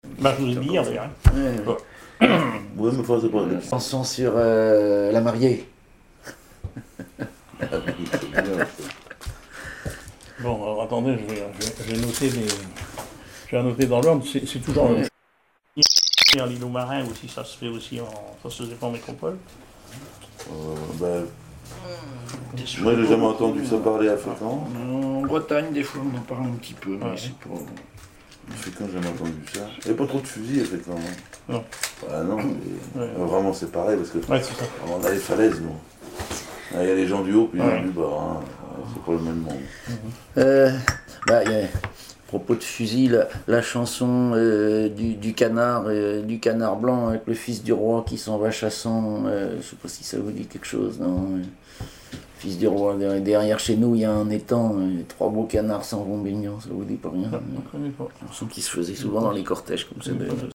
chanteur(s), chant, chanson, chansonnette
Catégorie Témoignage